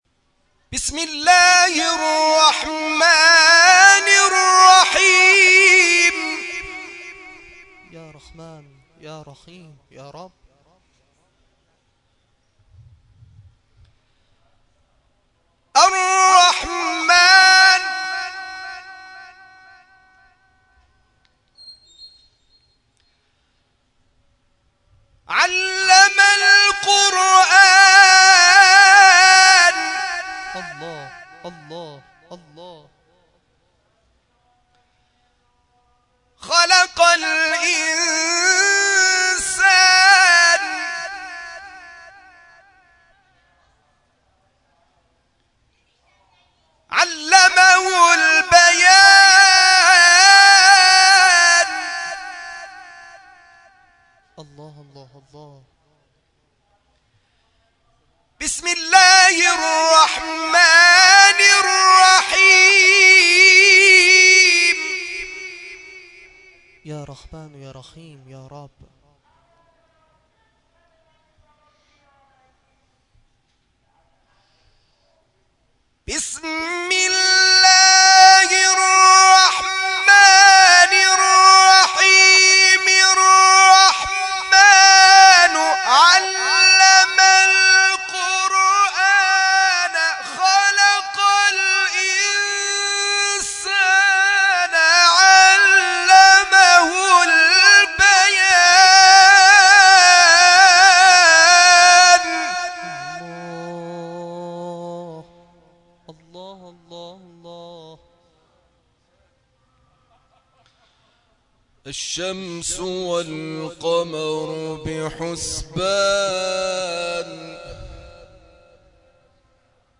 قاری بین‌المللی کشورمان در شب عاشورای حسینی در تکیه تجریش به تلاوت آیاتی از کلام الله مجید پرداخت.